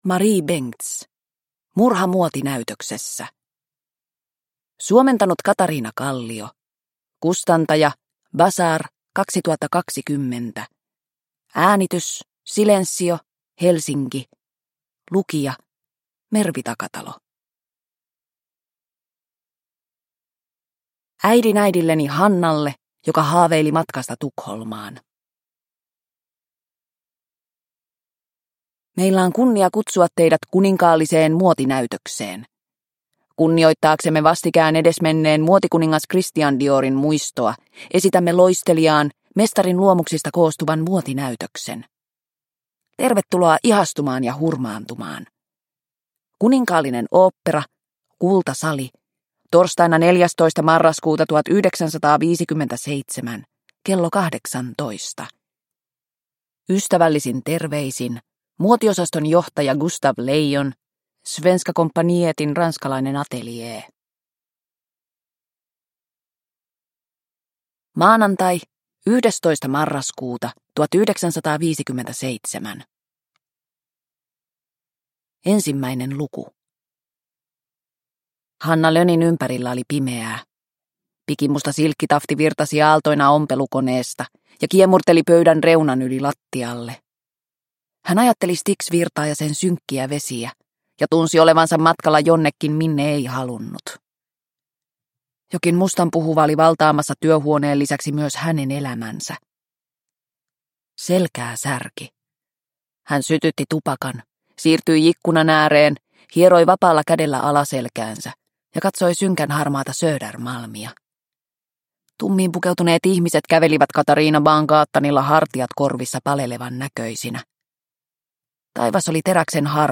Murha muotinäytöksessä – Ljudbok – Laddas ner